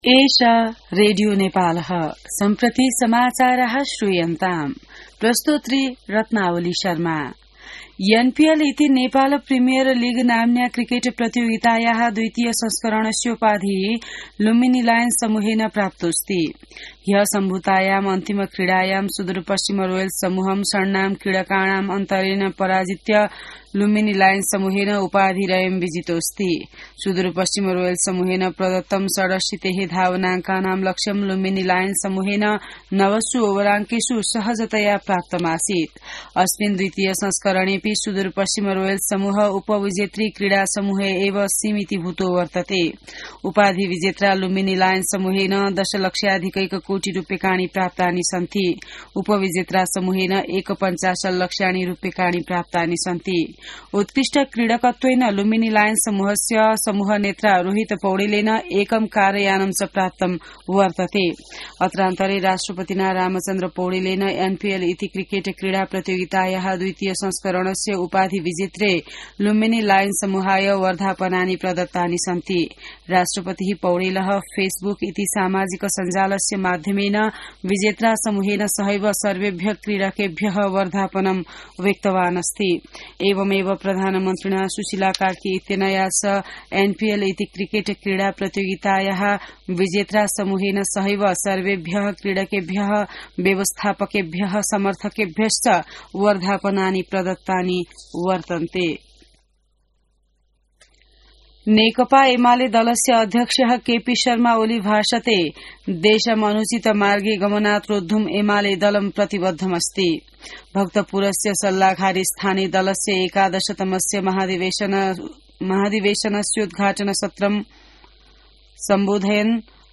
संस्कृत समाचार : २८ मंसिर , २०८२